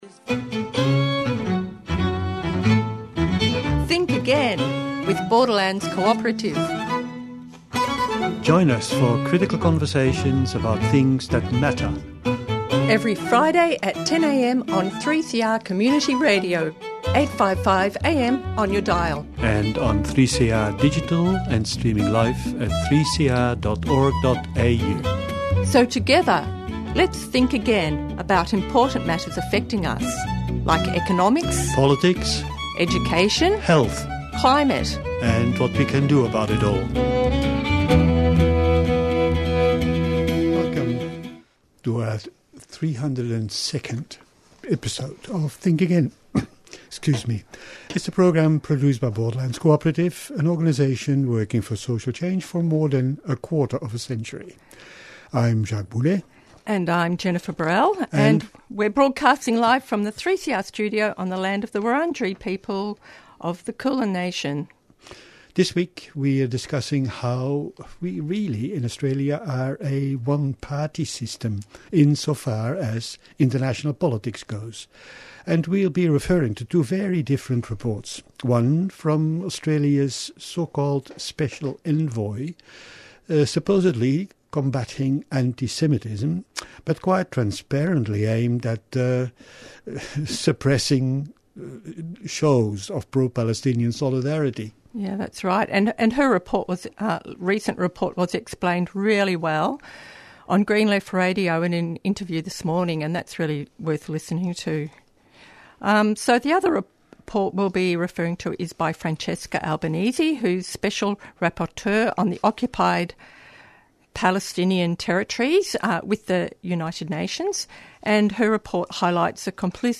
Tweet Think Again Friday 10:00am to 10:30am Think Again offers weekly conversations and reflections about current events, trends and public pronouncements on contemporary and emerging issues.